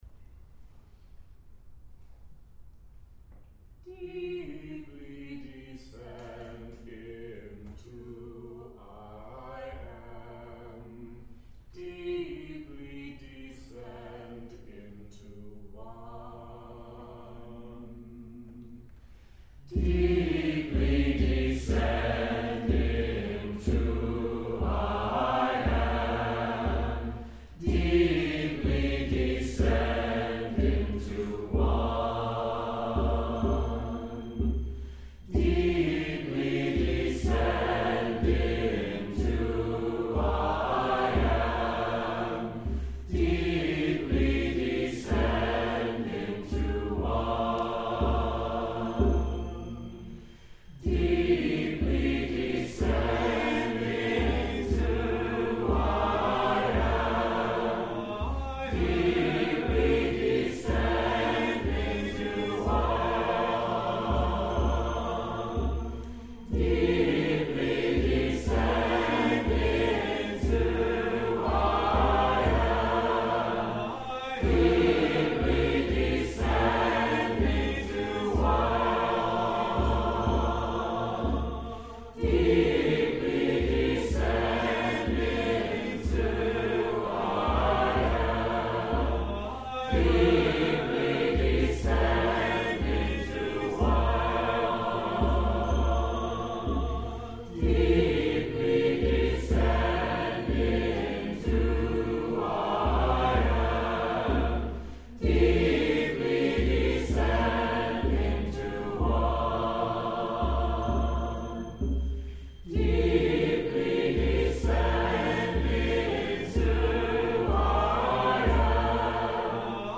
Chant: